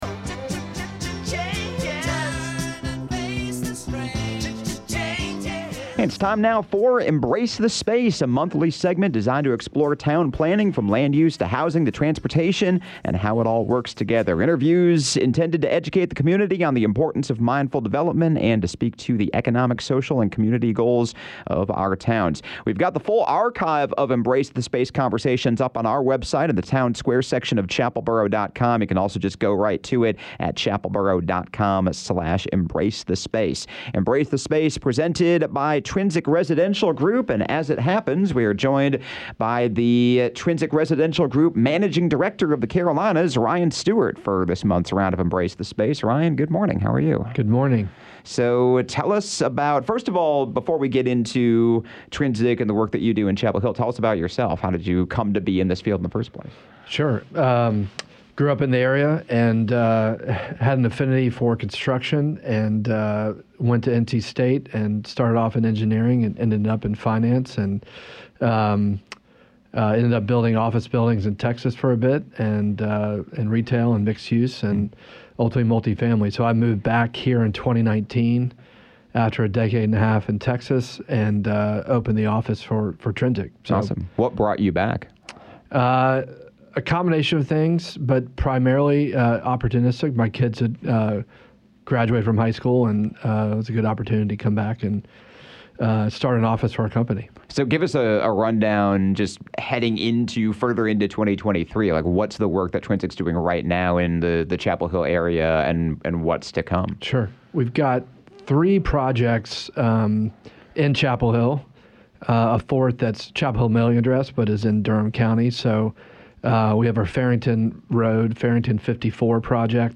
A monthly segment presented by Trinsic Residential Group, “Embrace The Space” features conversations with experts and professionals in town planning, discussing how differing concepts — from land use and housing to transportation and beyond – all come together to create a community that works, and works for people.